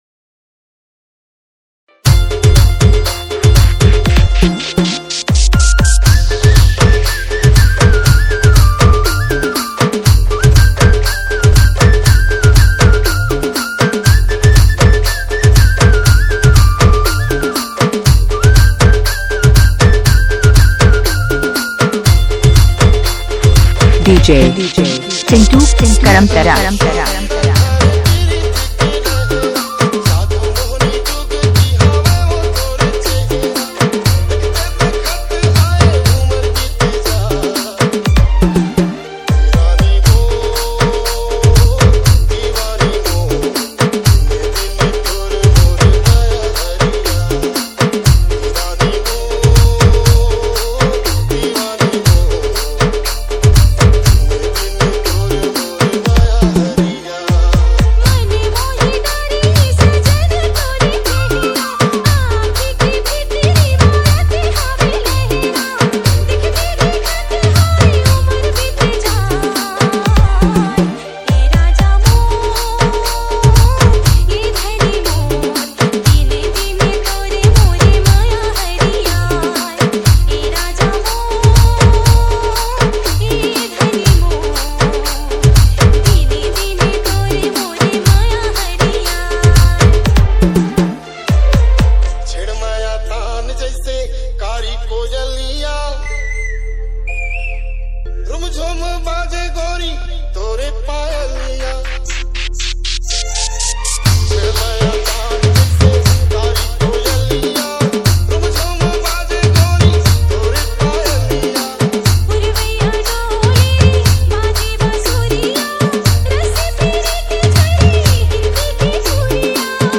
CG LOVE DJ REMIX